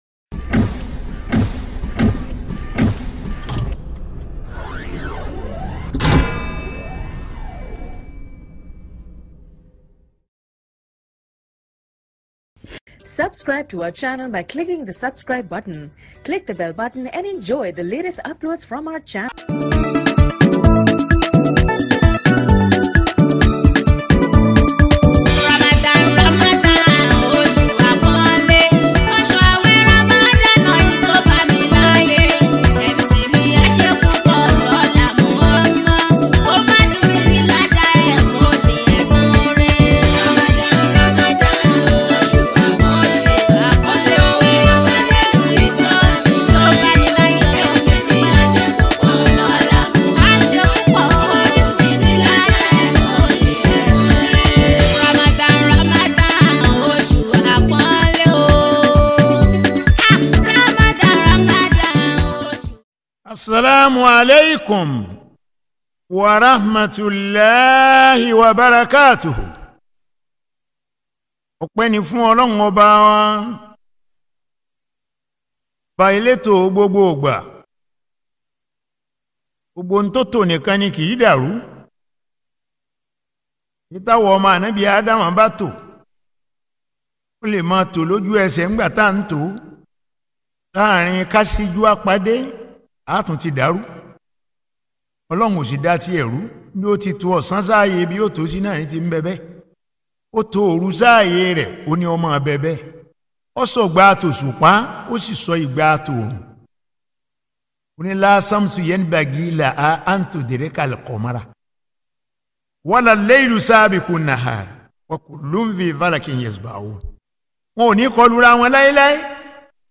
April 14, 2021 FujiNaija Music Wasi Islamic Lectures 0